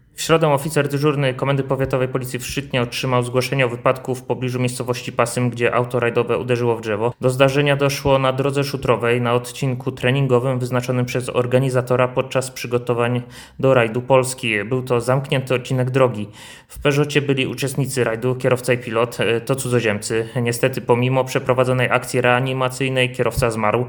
mówi Radiu 5